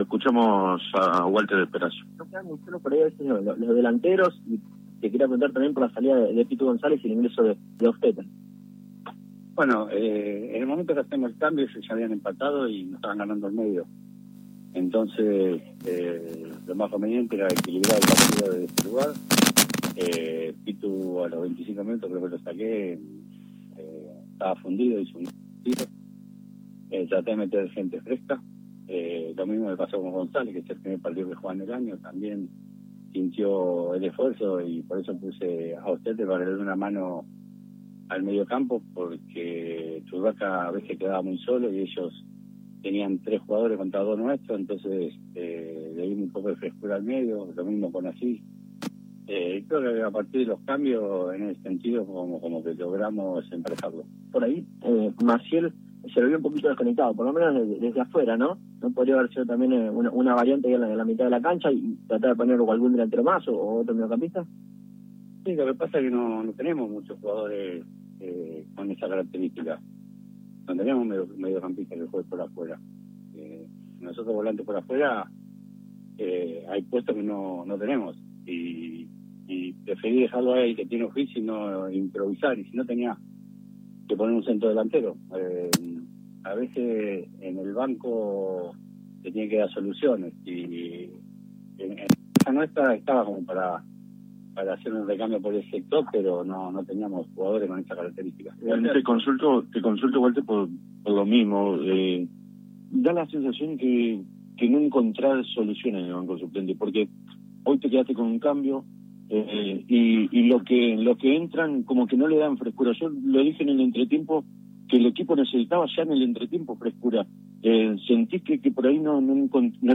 En rueda de prensa el DT del rojinegro no dio el mínimo atisbo de que esto suceda y se limitó a analizar el resultado y a expresar que hay que seguir trabajando, no haciendo reparo en lo que todos ven: un equipo sin brújula. con yerros defensivo, por demás apático y sin variantes a la hora de atacar.